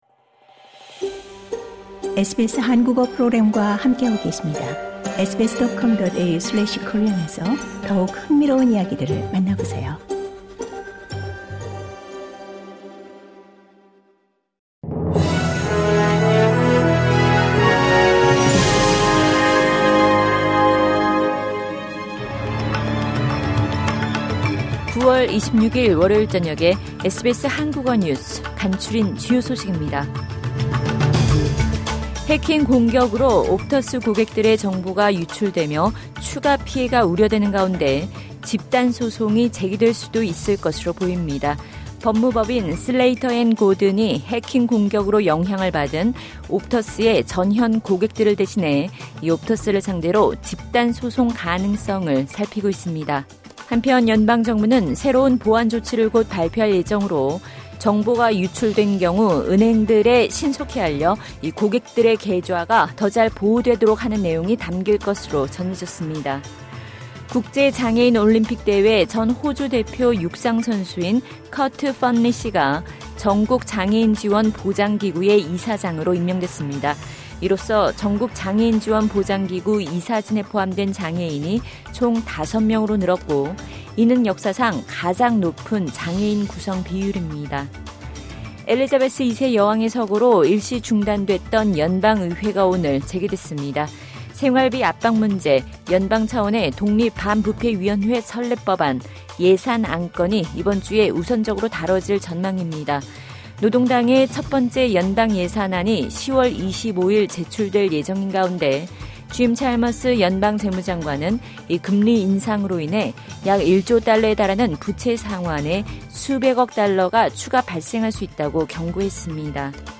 SBS 한국어 저녁 뉴스: 2022년 9월 26일 월요일